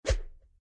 raise_hand-CUbxEnt9.ogg